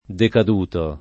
DOP: Dizionario di Ortografia e Pronunzia della lingua italiana
vai all'elenco alfabetico delle voci ingrandisci il carattere 100% rimpicciolisci il carattere stampa invia tramite posta elettronica codividi su Facebook decaduto [ dekad 2 to ] part. pass. di decadere e agg.